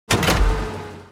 دانلود آهنگ تانک 5 از افکت صوتی حمل و نقل
جلوه های صوتی
دانلود صدای تانک 5 از ساعد نیوز با لینک مستقیم و کیفیت بالا